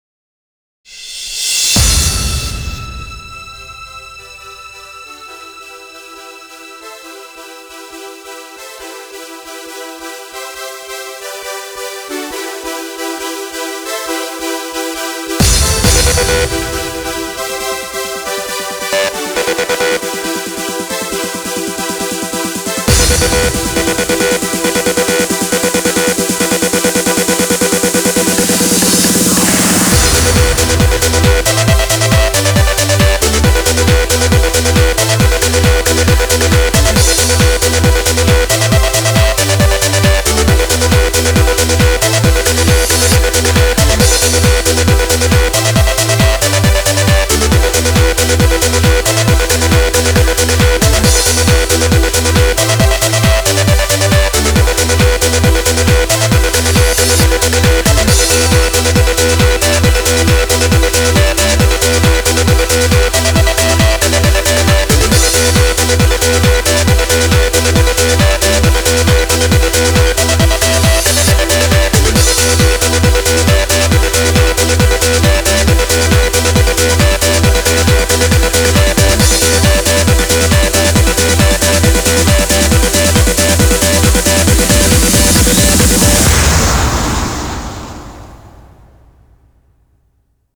BPM136
Audio QualityPerfect (High Quality)
Comentarios[90s HARD TRANCE]